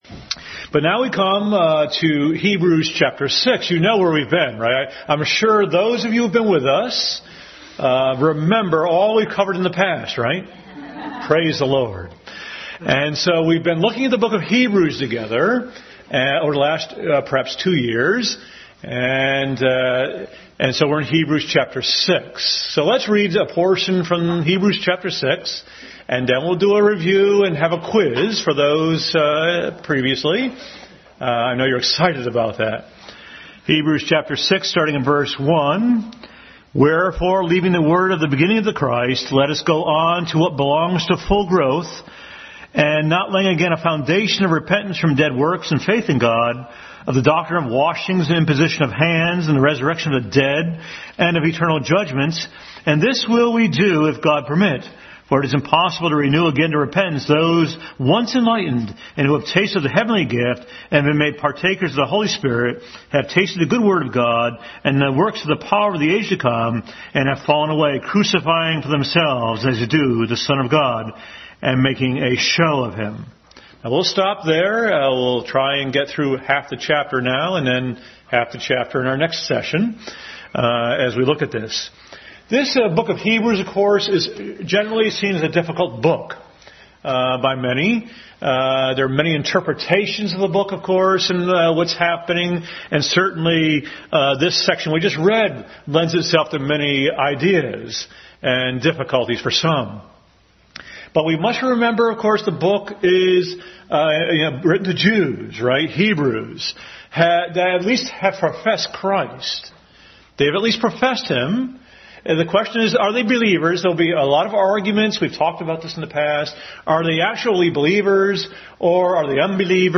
Passage: Hebrews 6 Service Type: Sunday School